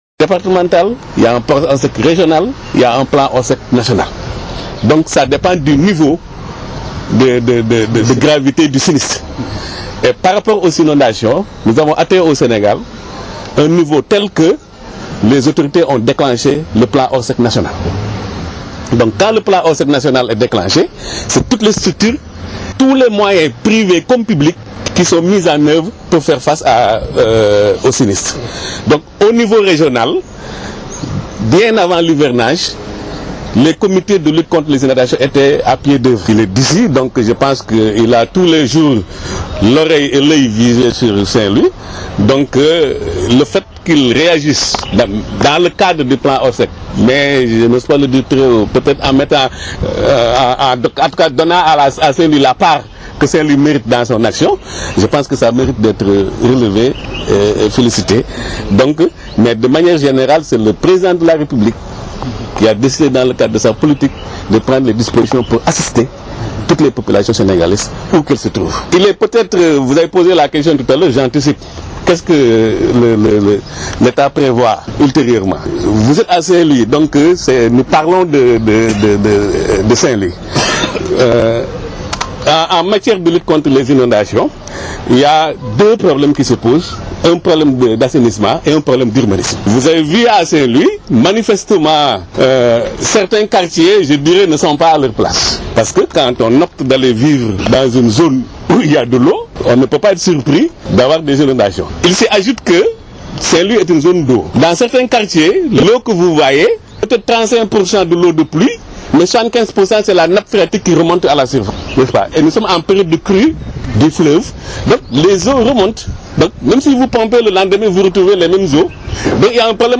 Plusieurs pelles, brouettes, gants autres matériels ont été offerts par la direction de la protection et de la solidarité nationale. Ecoutez la réaction du Gouverneur.